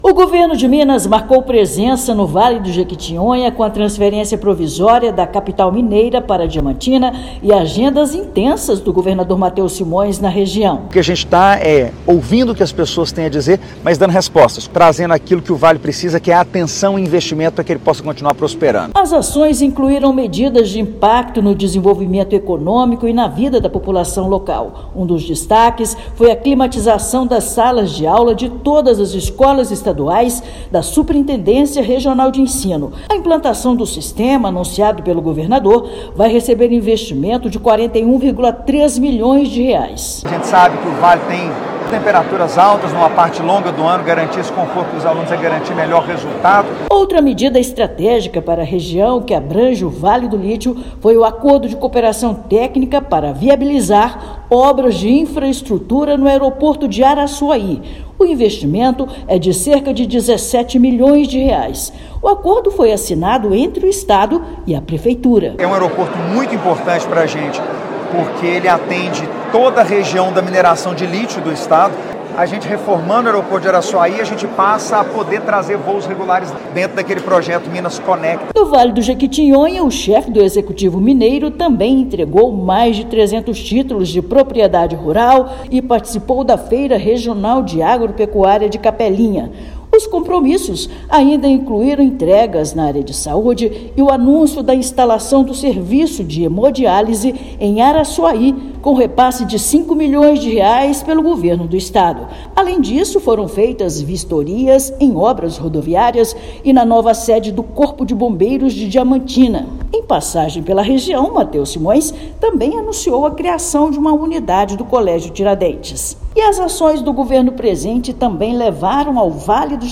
O balanço das ações destaca recurso de R$17 milhões para reforma do aeroporto de Araçuaí, climatização de salas de aula e repasse de R$ 5 milhões para serviço de hemodiálise. Ouça matéria de rádio.